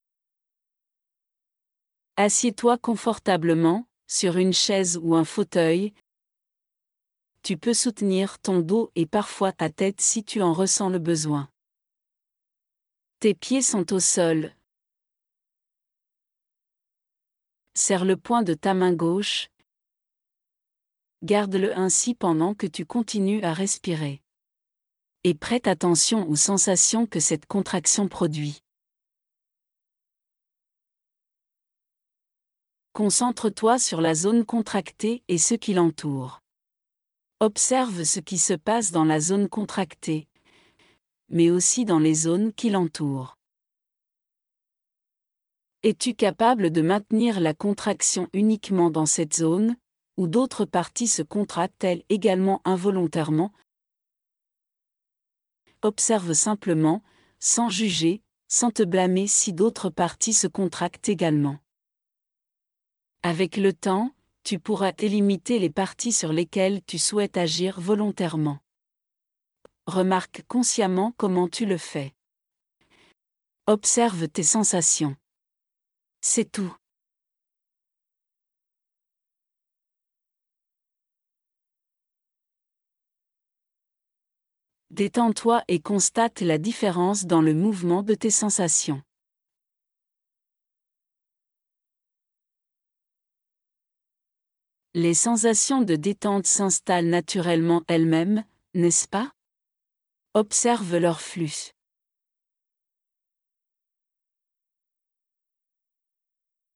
1.-RELAXATION-CORPORELLE-PROGRESSIVE.wav